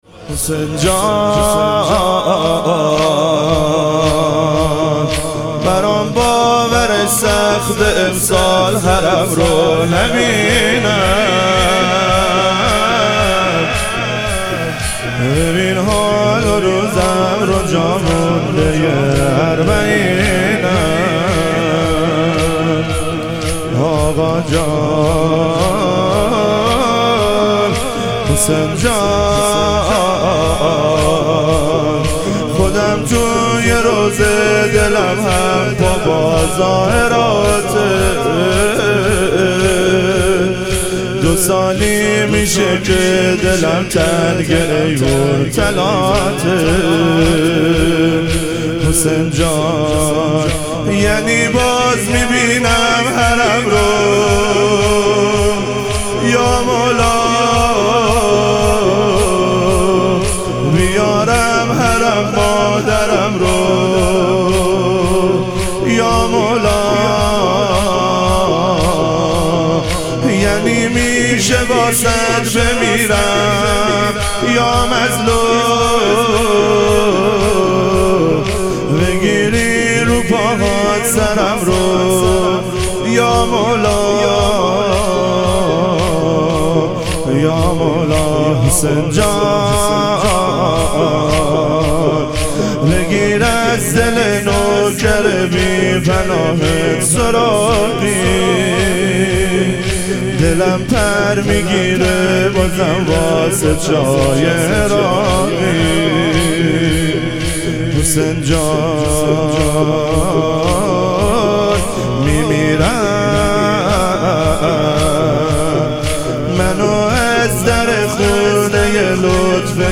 اربعین امام حسین علیه السلام - تک